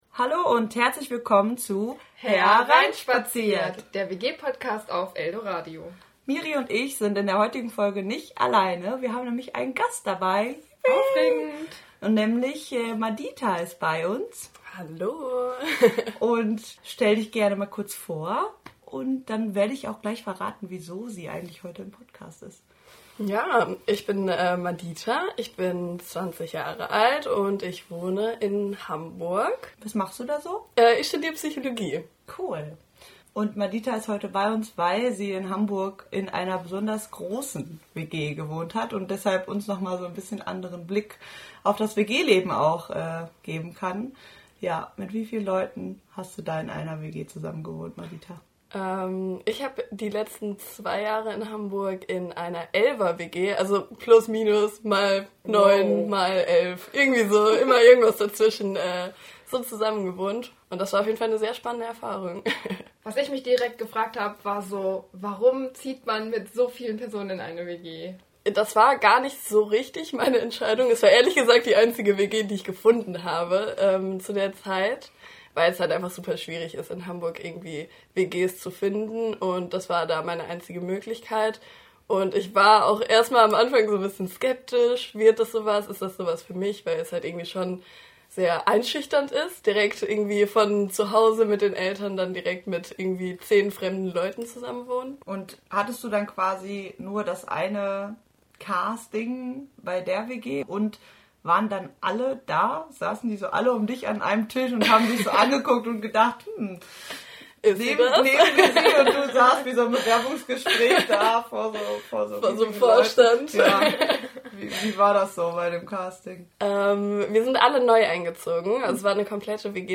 Hereinspaziert! #12 - Interview: Leben in einer 10er-WG